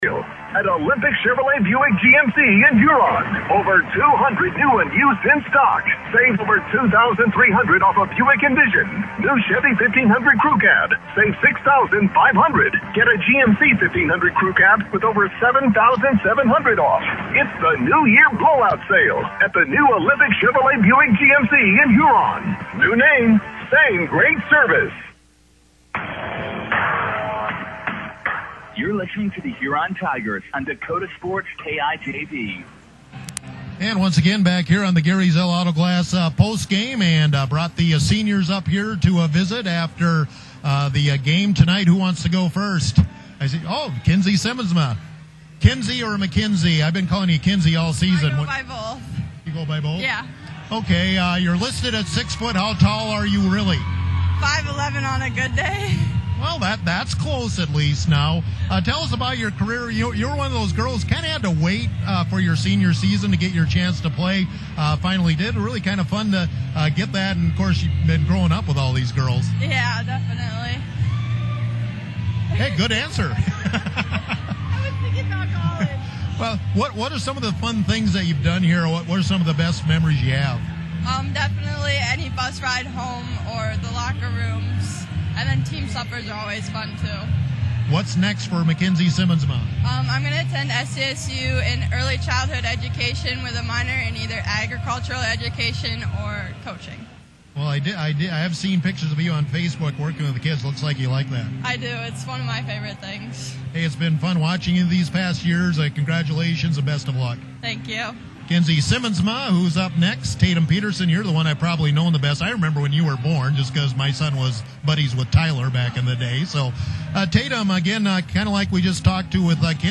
Tigers Senior Girls Interviews